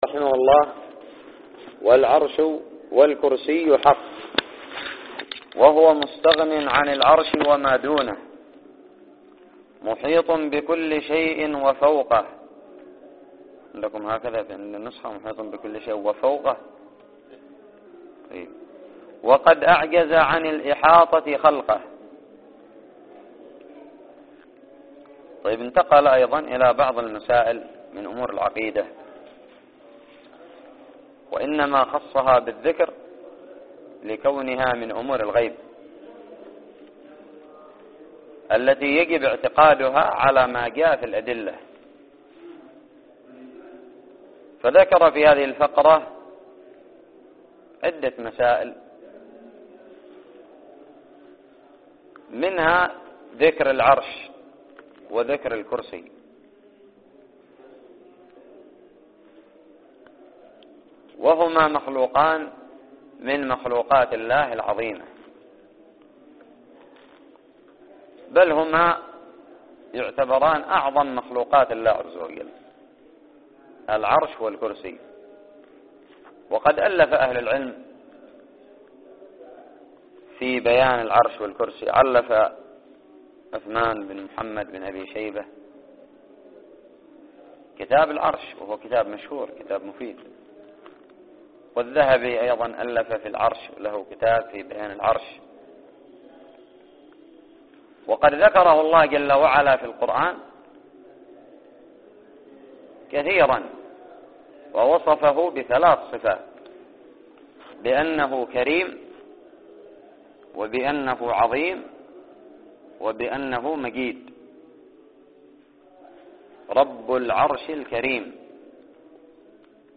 الدرس الخامس والعشرون من شرح العقيدة الطحاوية
ألقيت في دار الحديث بدماج